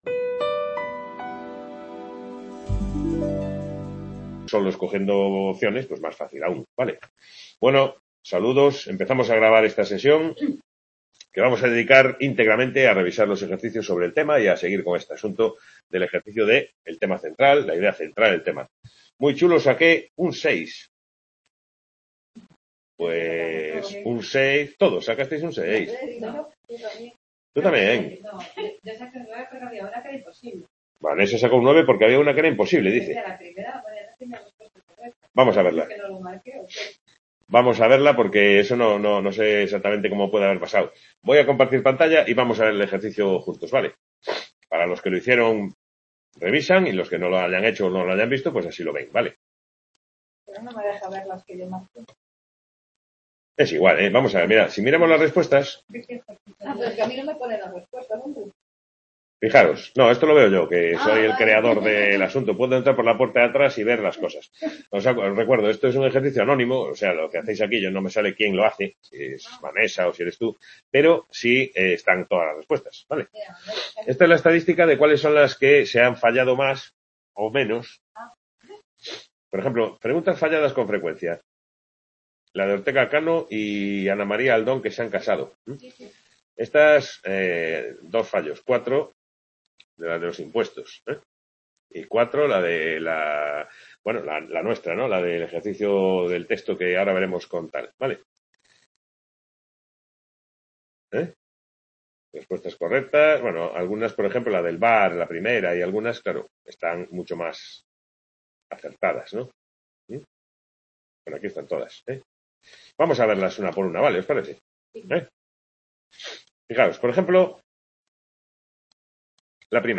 Sesión de tutoría (17/11/2021) Description Sesión de tutoría de la asignatura Comentario de Texto, dedicada a la discriminación del tema o idea central de un texto.